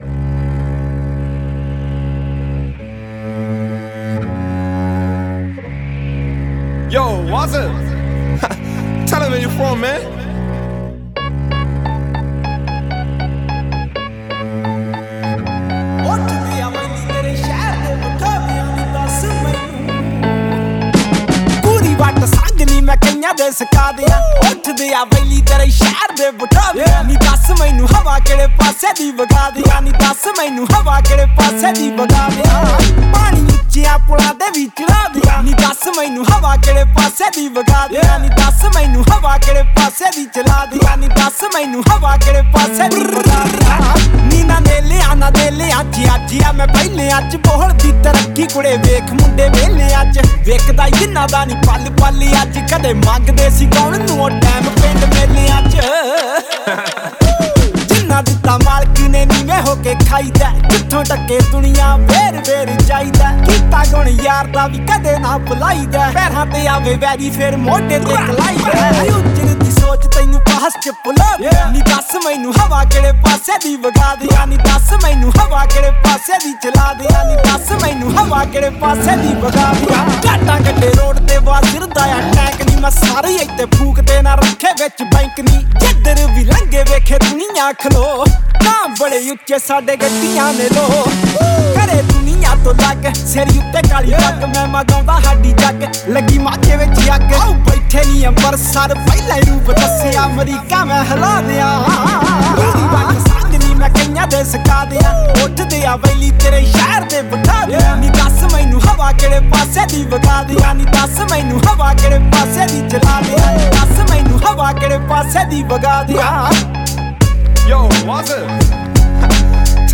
New Punjabi Song